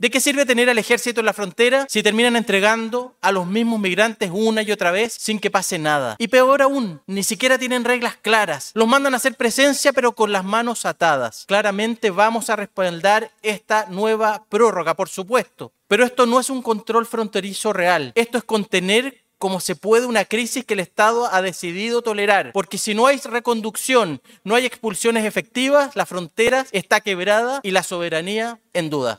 Sin embargo, el debate mostró posturas encontradas. El diputado por Tarapacá, Renzo Trisotti (Republicanos), apoyó la prórroga pero criticó duramente la falta de efectividad en las expulsiones.